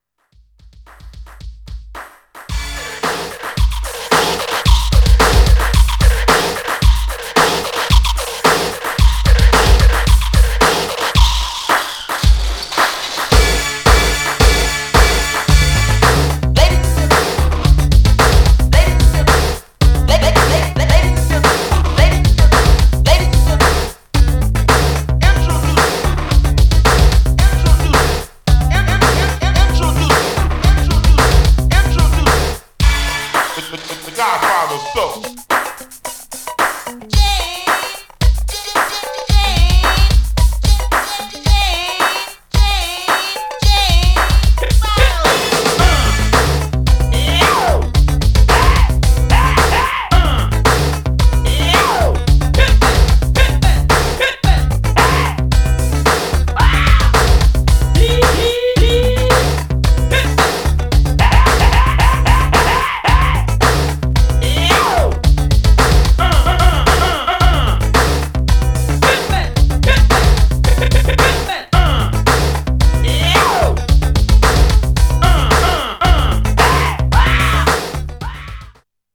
Styl: Hip Hop, Breaks/Breakbeat